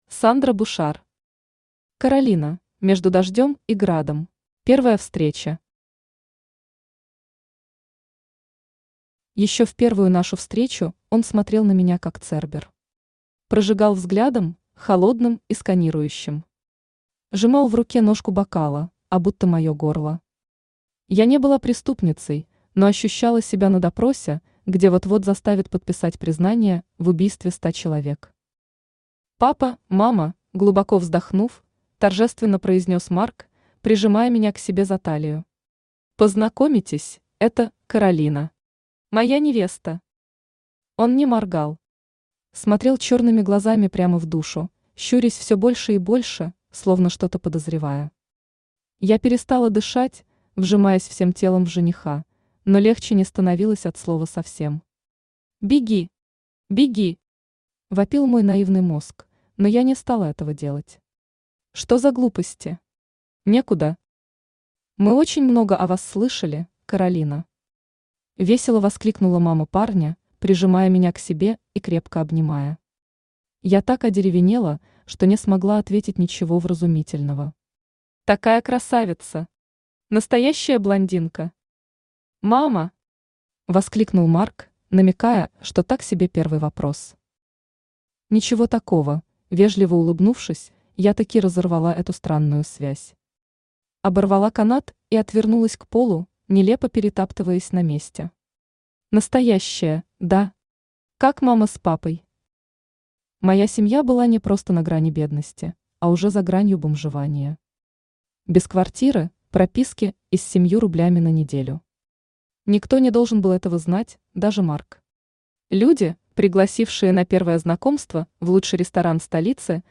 Аудиокнига Каролина: между дождем и градом | Библиотека аудиокниг
Aудиокнига Каролина: между дождем и градом Автор Сандра Бушар Читает аудиокнигу Авточтец ЛитРес.